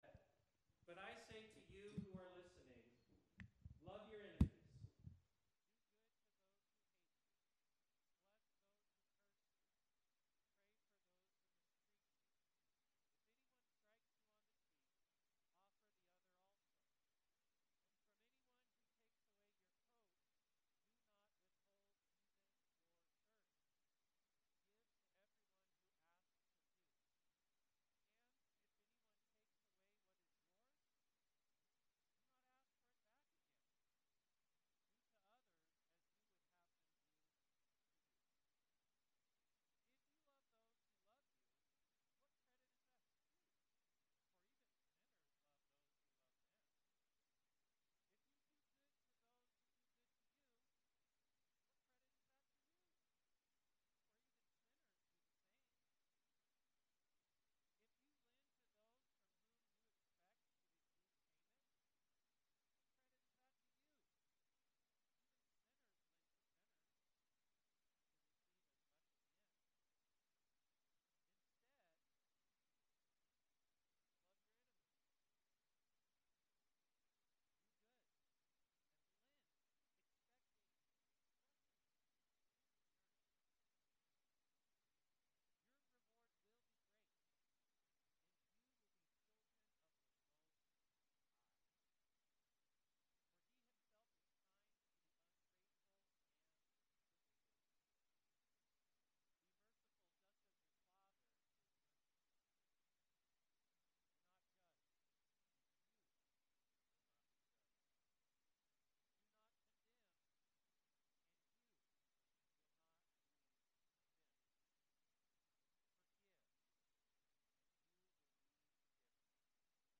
Sermon 06.29.25